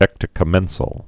(ĕktə-kə-mĕnsəl)